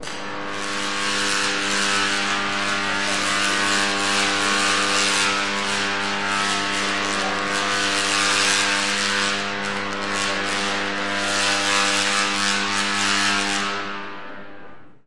描述：一系列积木声音效果中的一个记录在一个有一些植物物质和15kv 0.25a供应的棚子里。 在这个夹子里，一个自闭式变压器被卷起来的材料开始沸腾，水被电流加热，当材料被分解成碳时，电弧开始形成，因为它烧掉了电弧跳空气而你听到它变得不稳定，直到电路断开，最后压力变压器被压缩。
标签： 星火 15KV 高压 电弧 烧伤
声道立体声